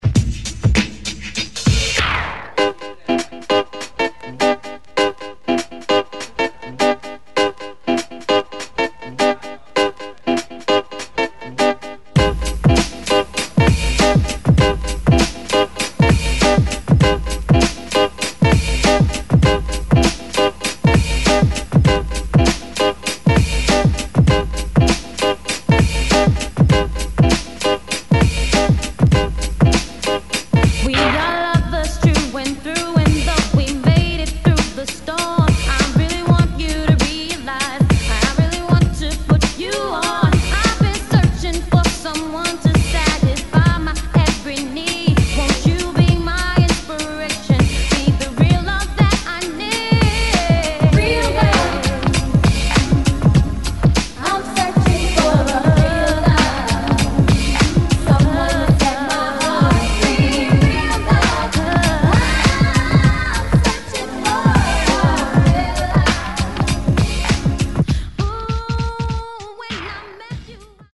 100 Bpm Genre: 90's Version: Clean BPM: 100 Time